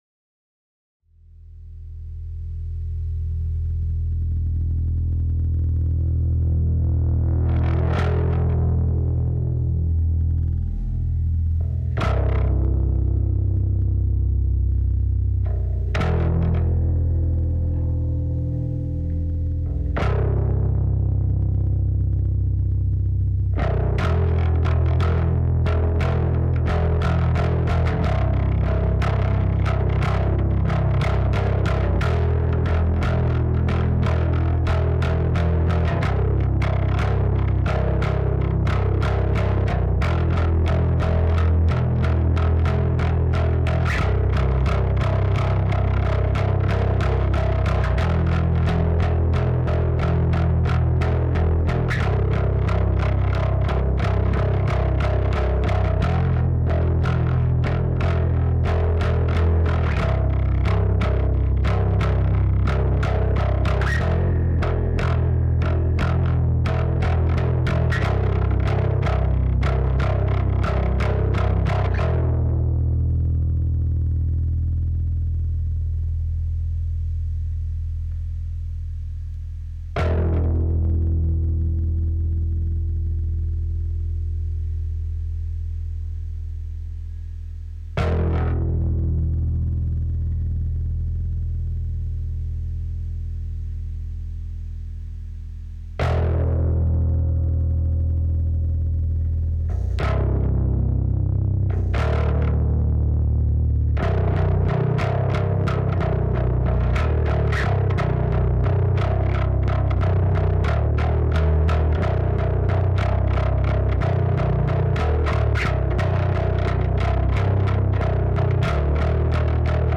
beide Spuren auf ne Gruppe, dort ein bissel mit dem Cubase Maximizer gefummelt, voila, klingt geil tolle Arbeitsgrundlage für den eigentliche Mix der ja noch ansteht. Danke euch Edit: hier mal das File der Bassgruppe, die Timingschwankungen hier und da sind bislang noch ignoriert von mir da sie im Mix nicht wirklich auffallen, wenn mich die Langeweile plagen sollte editier ich das noch, aber an sich mach ich kaum noch Editing, aus Prinzip ab dafür: Anhänge Bassgruppe.mp3 Bassgruppe.mp3 12,4 MB · Aufrufe: 251